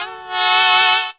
HARM SWELL.wav